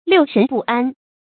六神不安 注音： ㄌㄧㄨˋ ㄕㄣˊ ㄅㄨˋ ㄢ 讀音讀法： 意思解釋： 六神：道家認為人的心、肺、肝、腎、脾、膽各有神靈主宰，稱為六神。